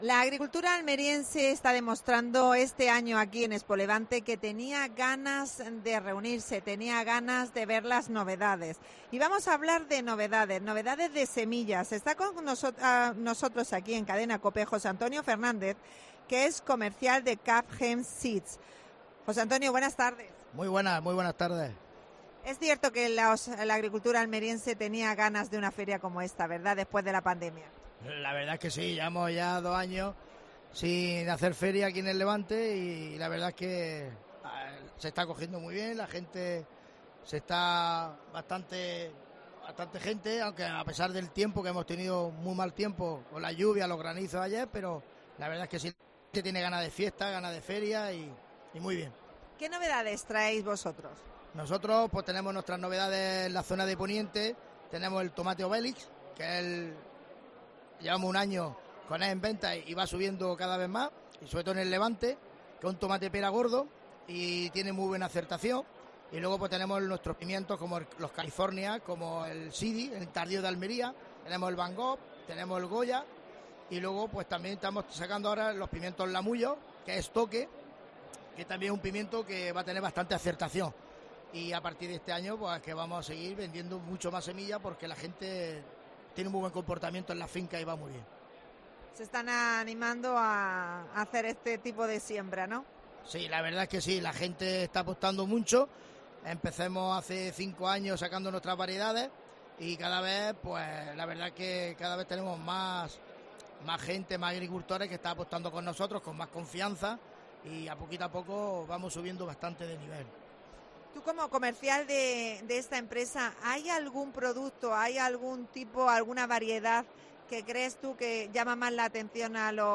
AUDIO: Especial ExpoLevante. Entrevista